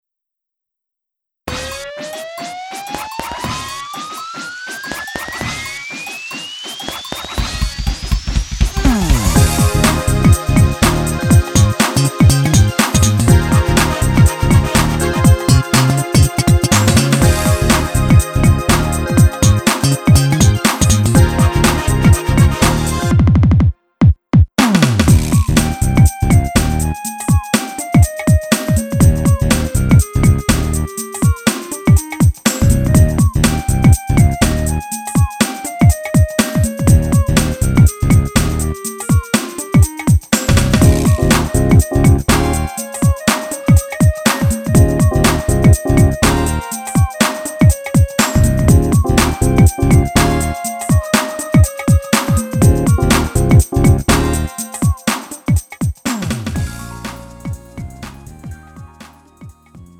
음정 원키
장르 가요 구분 Lite MR